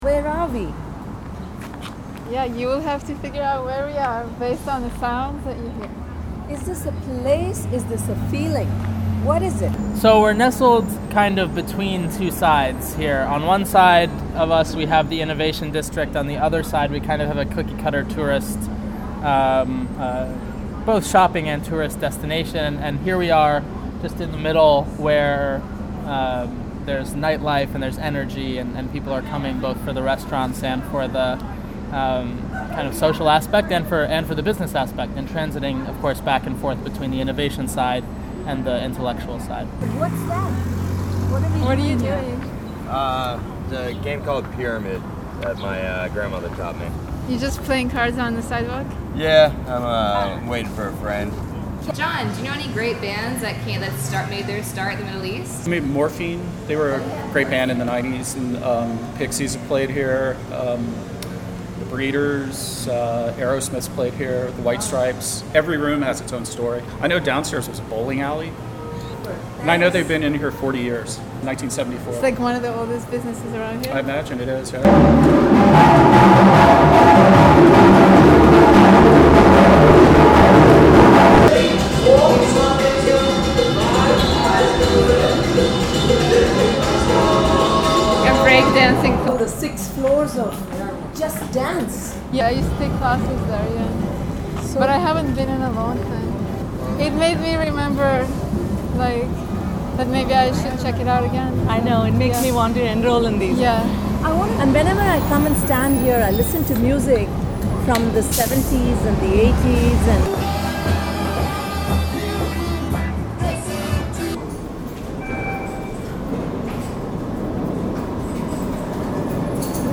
Is this merely a geographical location, or is it a feeling?Close your eyes, listen to identify where you are.